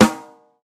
• Round Snare Drum Sound A Key 75.wav
Royality free steel snare drum sound tuned to the A note.
round-snare-drum-sound-a-key-75-Mmv.wav